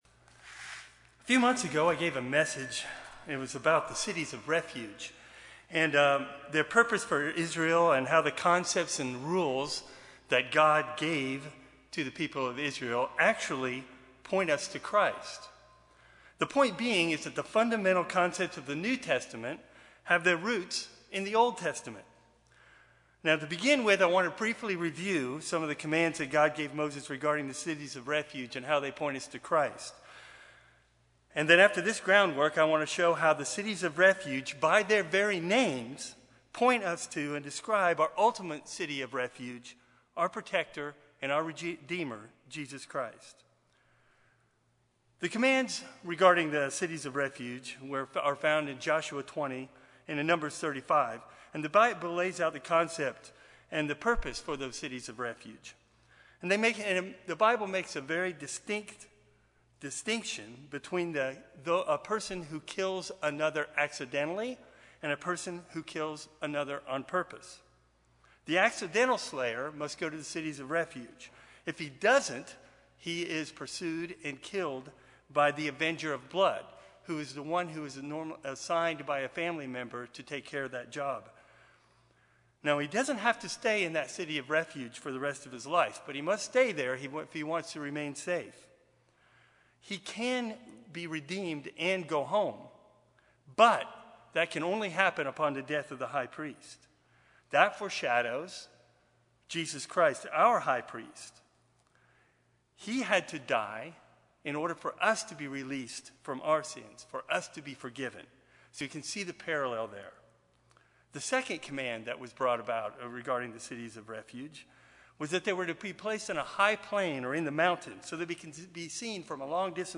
The fundamental concepts of the New Testament have their roots in the Old Testament, and actually foreshadow the coming Messiah. This message examines the laws related to Israel's cities of refuge and how, even by their very names, point directly to our savior and redeemer, Jesus Christ.